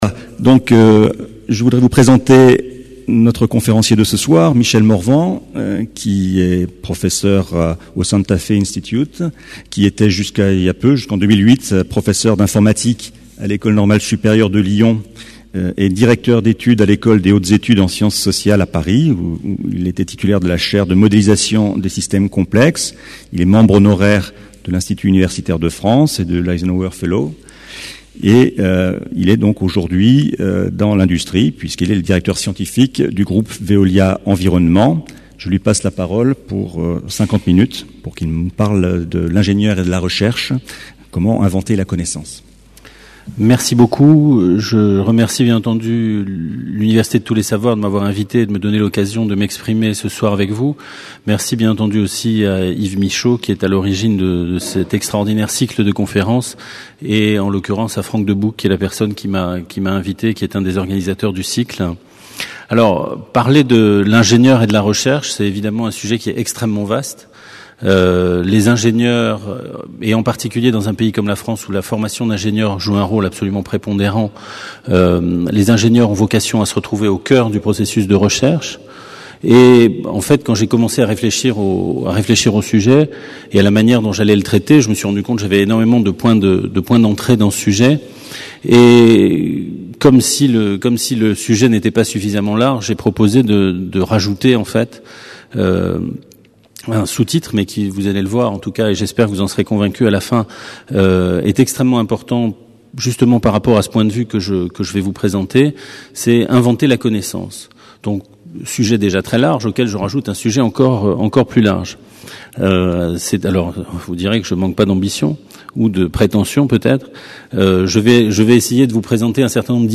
Une conférence du cycle : Qu'est-ce qu'un ingénieur aujourd'hui ?